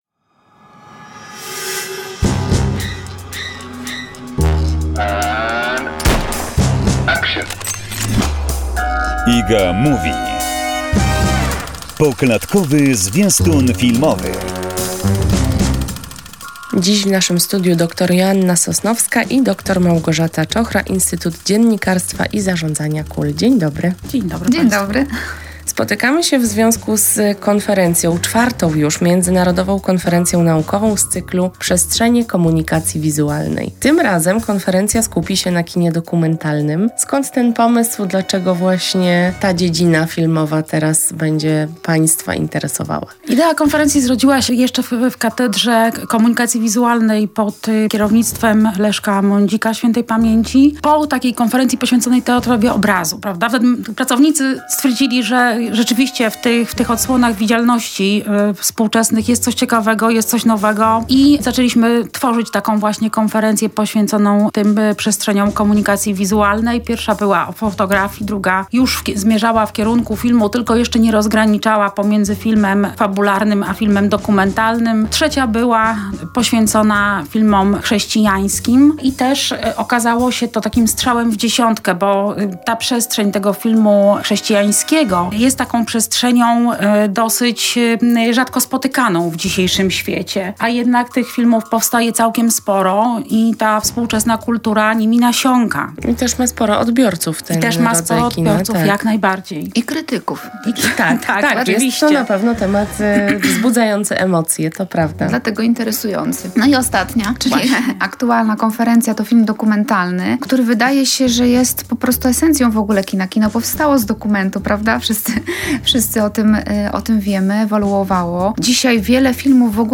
zaprasza do wysłuchania rozmowy